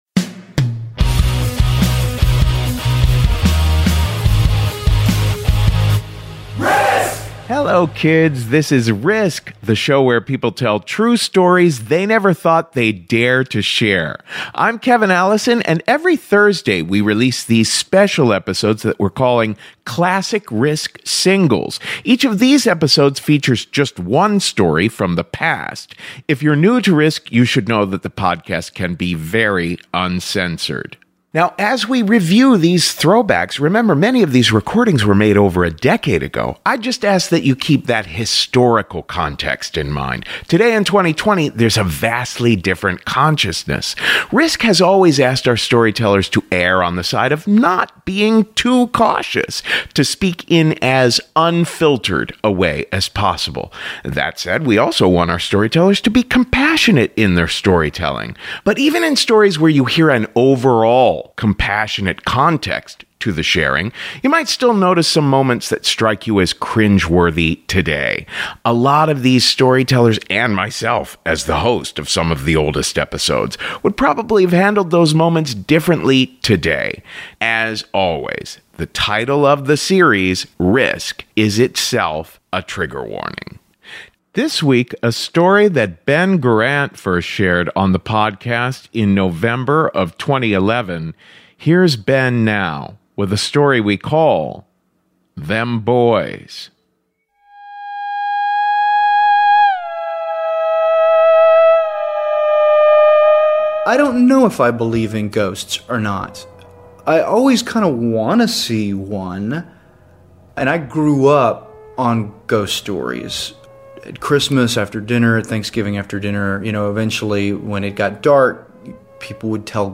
A Classic RISK! Singles episode. A story that Ben Garant first shared on the podcast in November of 2011 about hauntings in Tennessee.